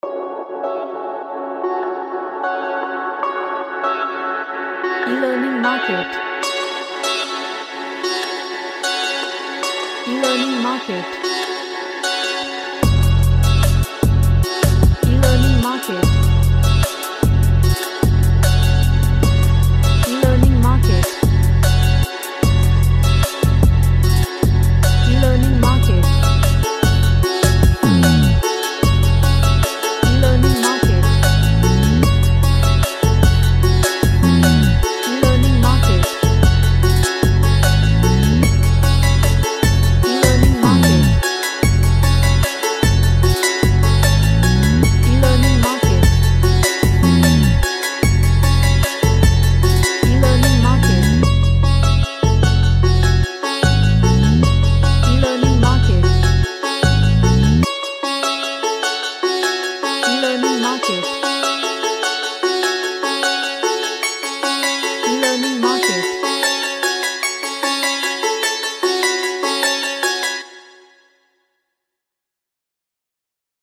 A hard indian trap music
Magical / MysticalHard